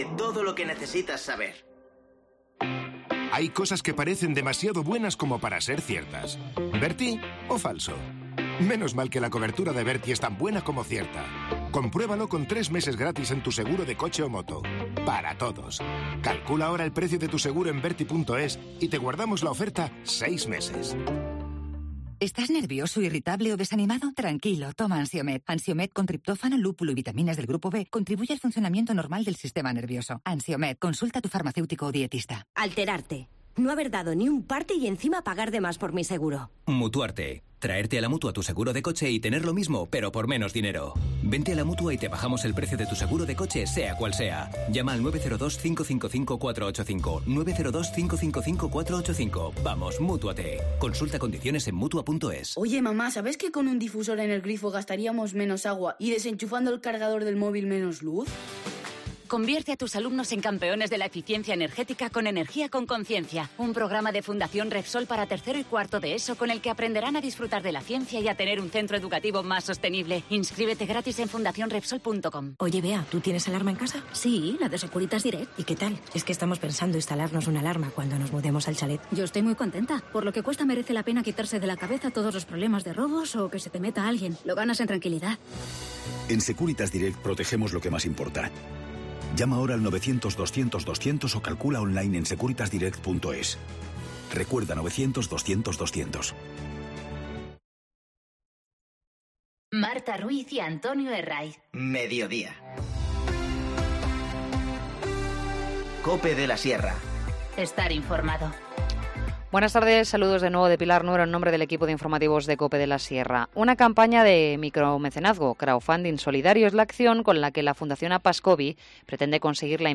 Informativo Mediodía 3 octubre 14:50h